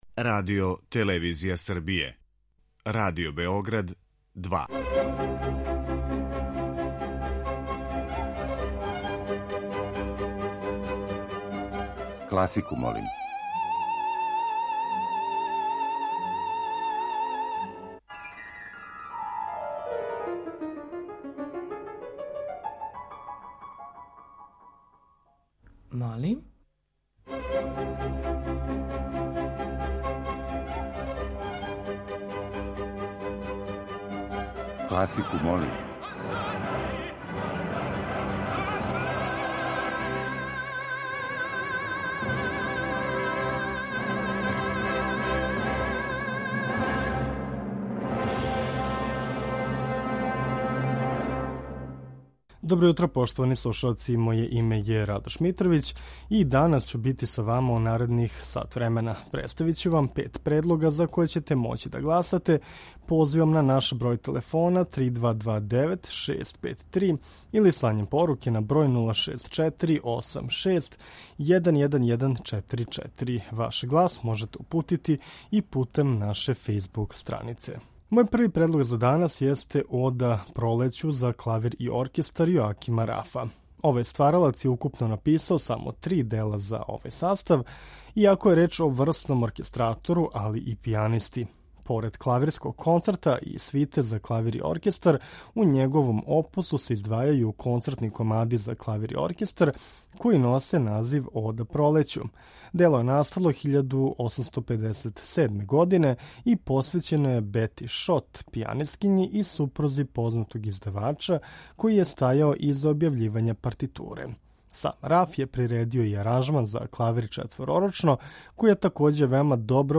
Окосница овонедељне емисије Класику, молим биће дела за кларинет.